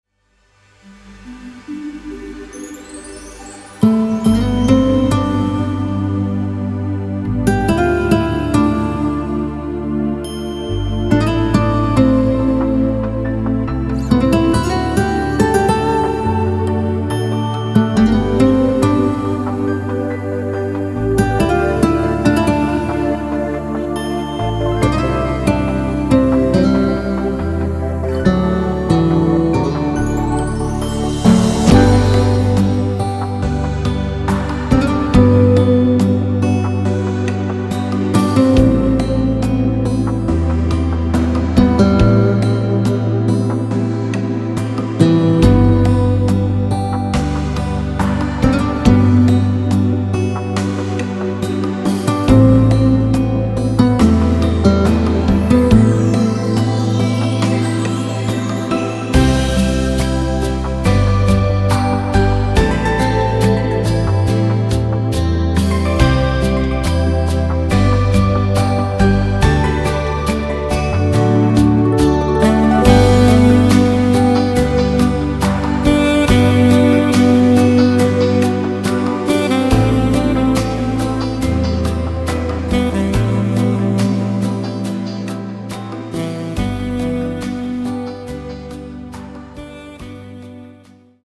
Særlige klangfulde sammensætninger.